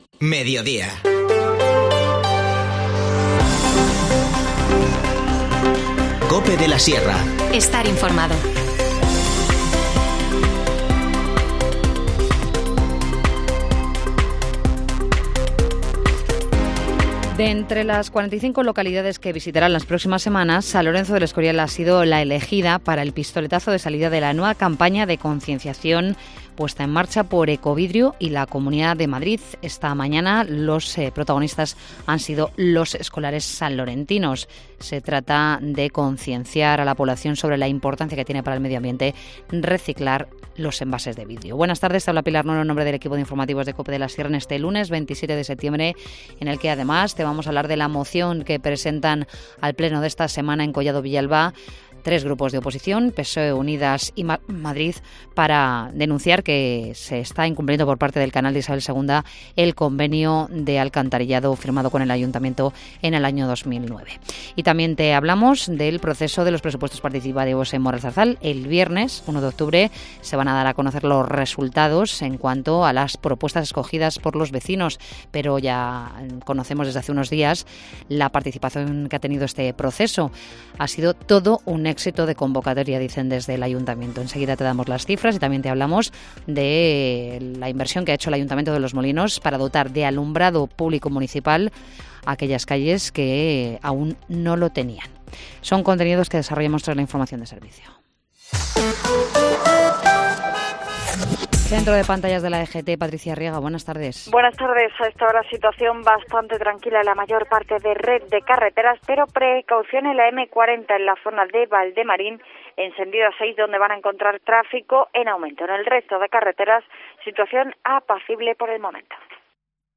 Informativo Mediodía 27 septiembre